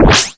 SE_Zoom3.wav